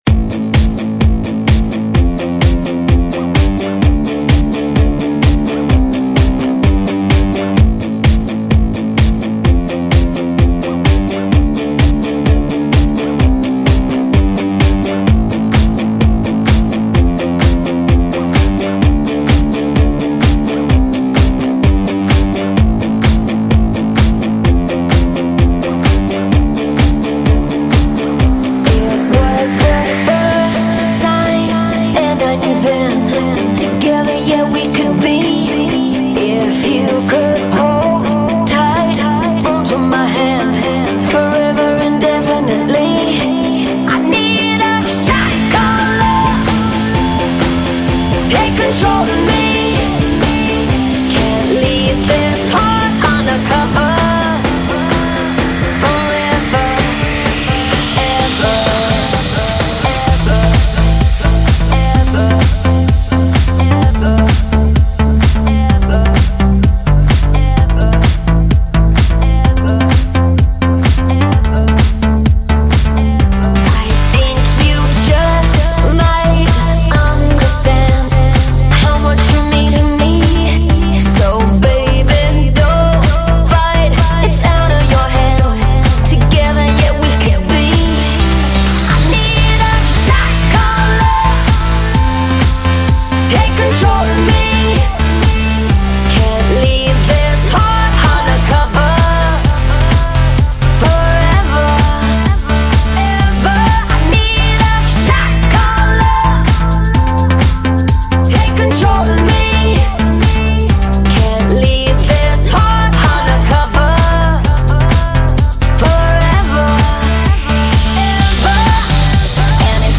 Главная » Electro, Progressive House